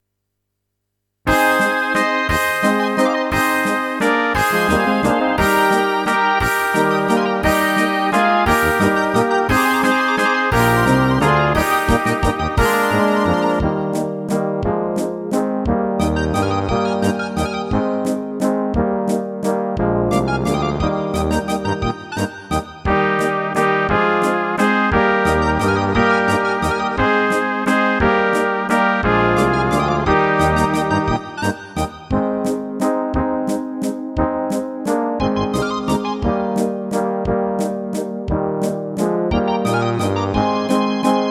Rubrika: Pop, rock, beat
- valčík